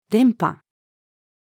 連覇-female.mp3